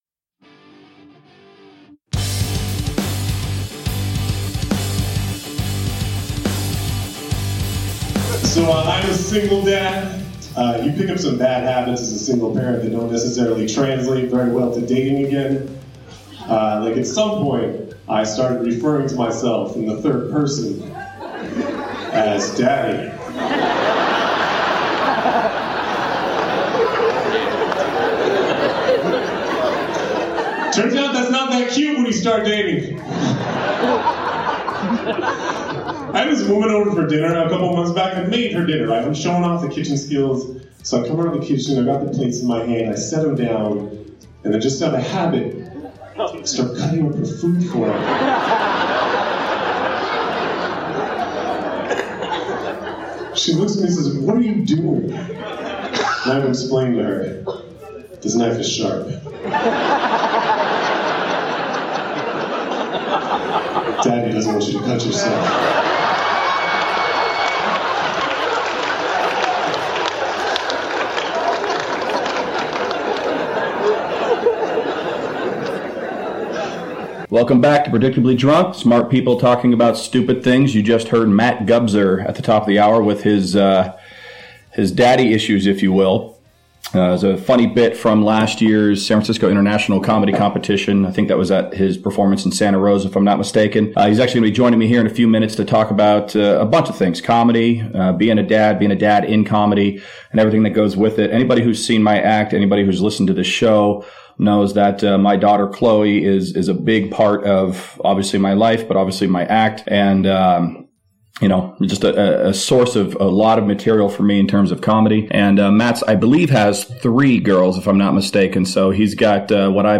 in studio this episode